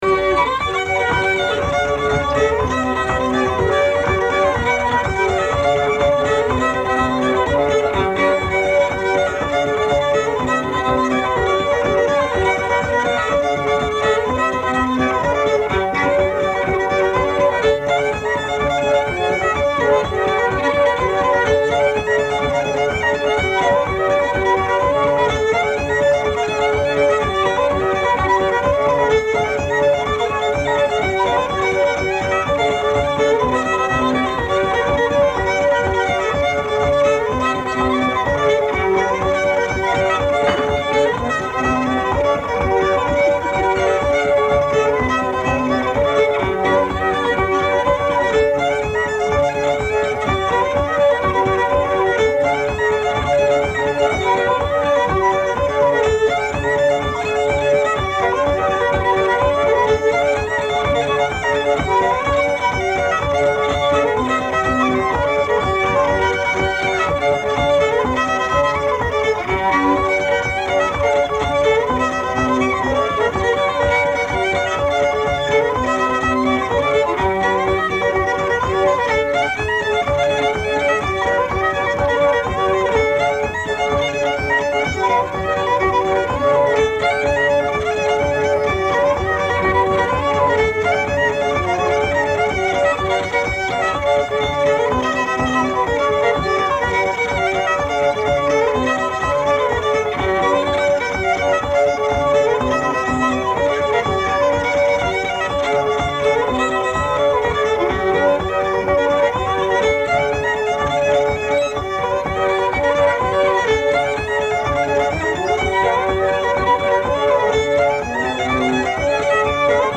pump organ